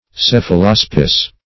Search Result for " cephalaspis" : The Collaborative International Dictionary of English v.0.48: Cephalaspis \Ceph`a*las"pis\, n. [NL., fr. Gr. kefalh` head + ? a shield.]